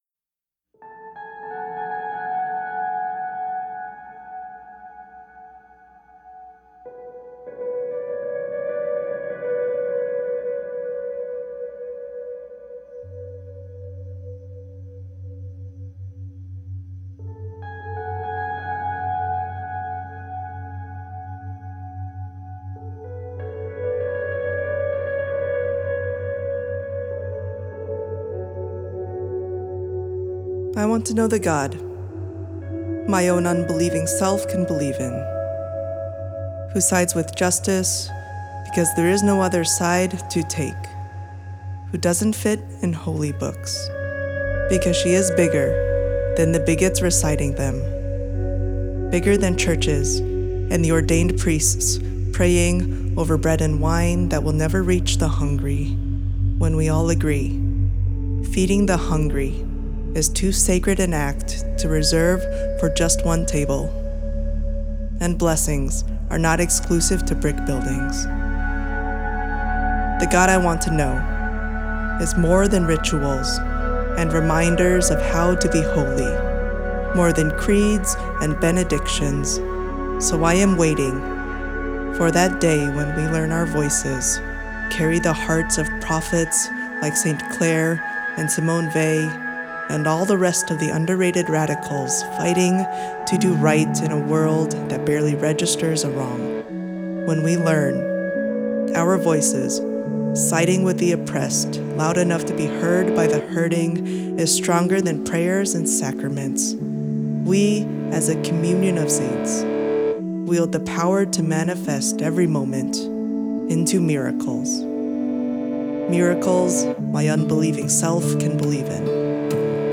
Spoken word/ music track